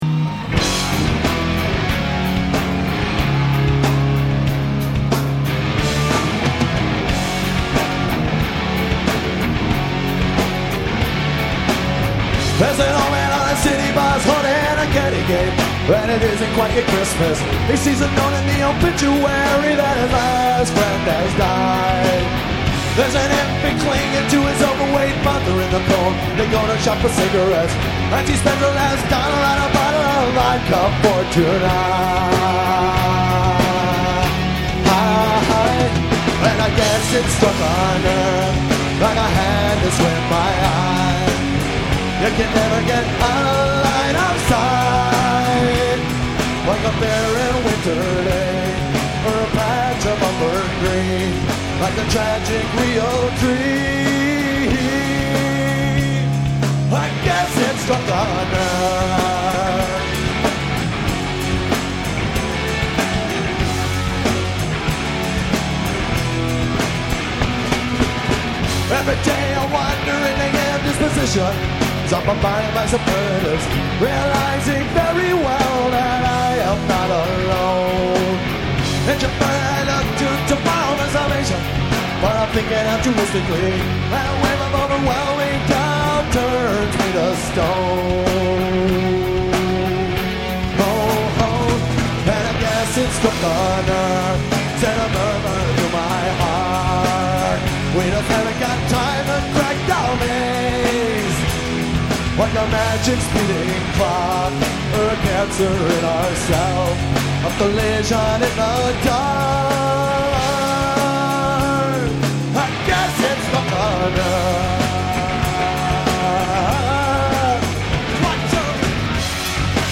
Tracks 2,3,6 and 7: live from KROQ Acoustic Xmas line